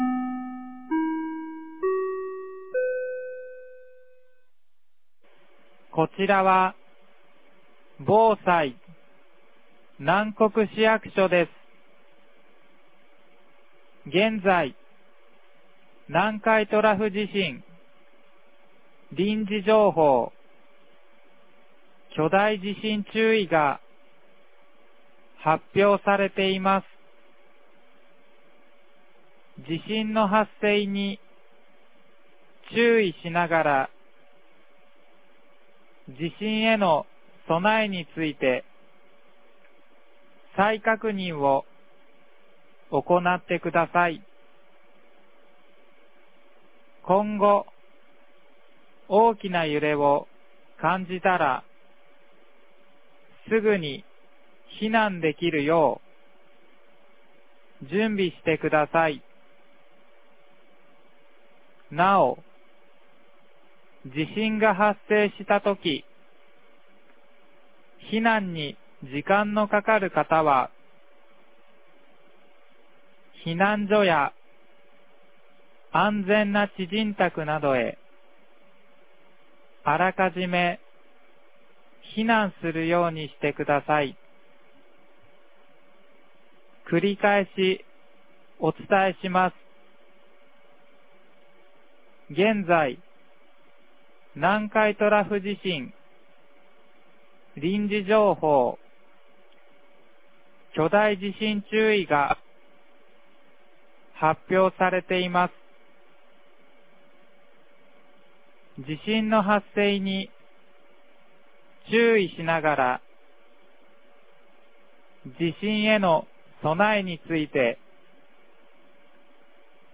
2024年08月09日 11時47分に、南国市より放送がありました。